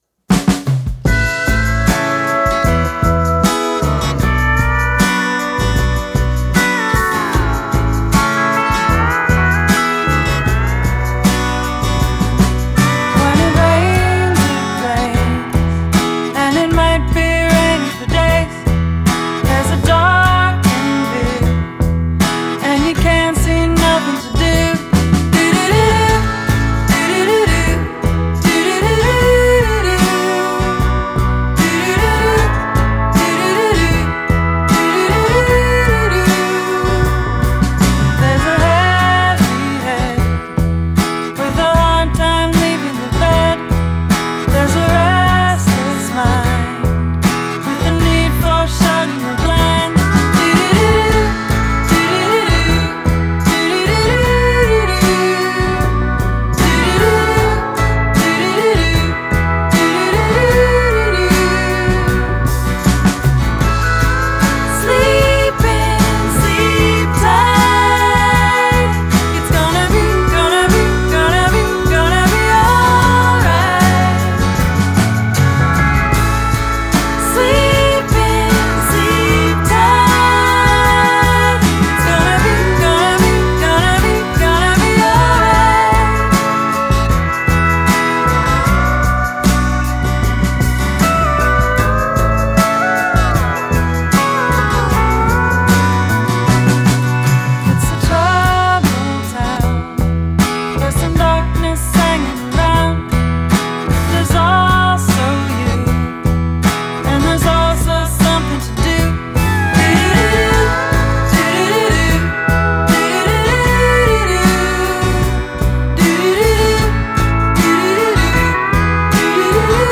Genre: Indie Folk